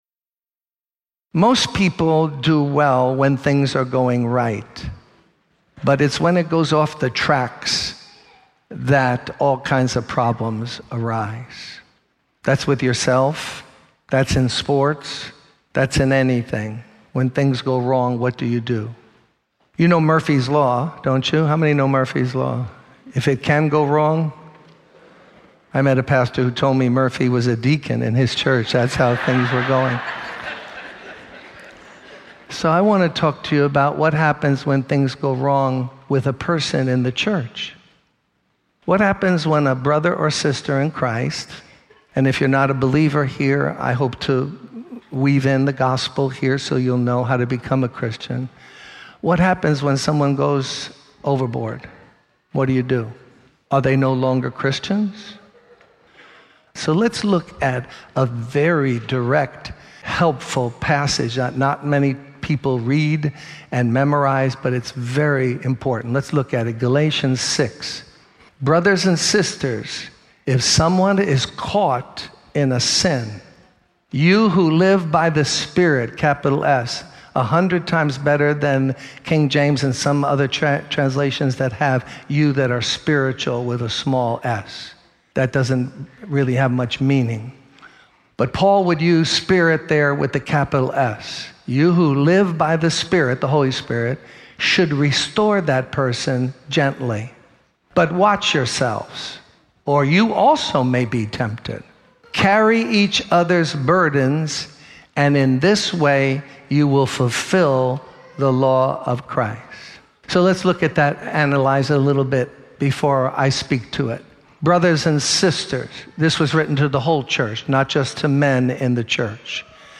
In this sermon, the preacher emphasizes the importance of Christians supporting and restoring one another when they fall into sin.